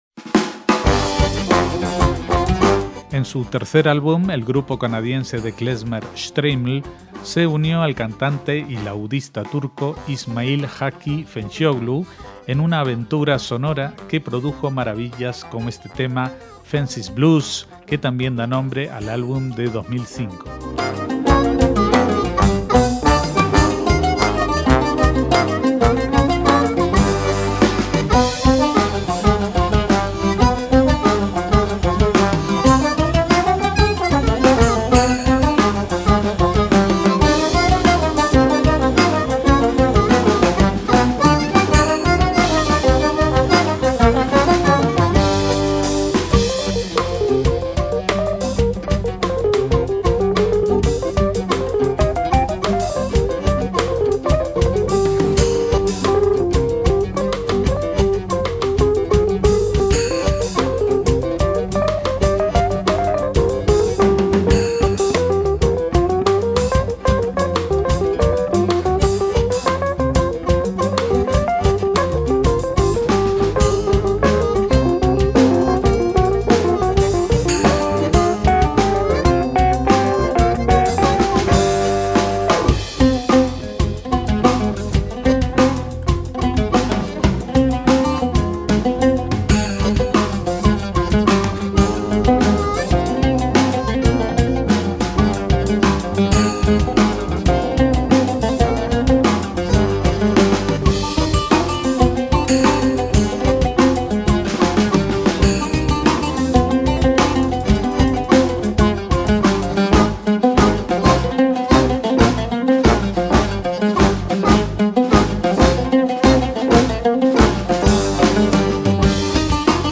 El cantante e intérprete de laúd árabe
la innovadora banda de klezmer
percusiones
trombón
bajo